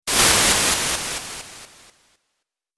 دانلود آهنگ موج 11 از افکت صوتی طبیعت و محیط
دانلود صدای موج 11 از ساعد نیوز با لینک مستقیم و کیفیت بالا
جلوه های صوتی